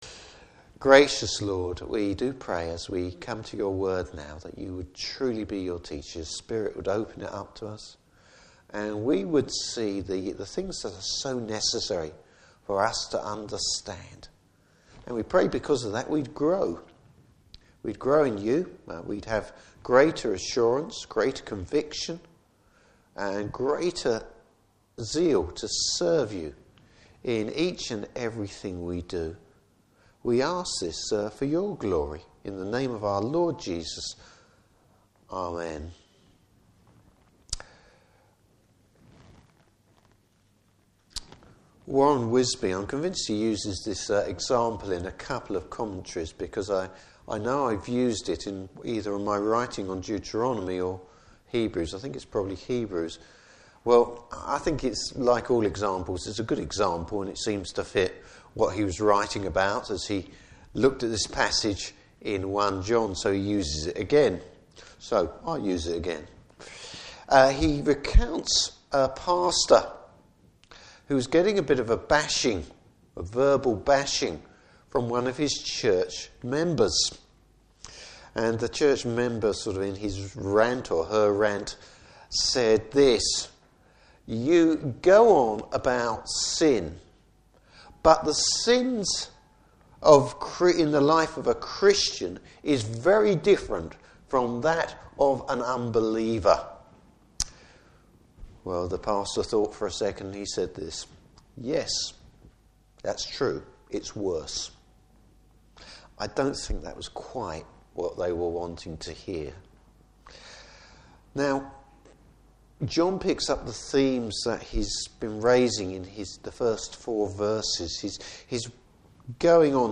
Service Type: Evening Service Bible Text: 1 John 1: 5-10.